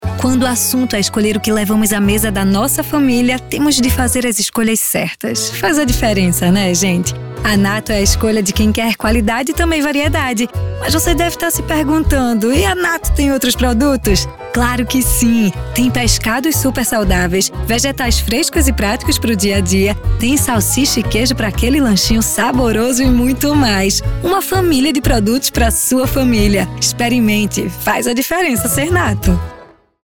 SPOT_Rdio_FazDiferenaSer_Natto.mp3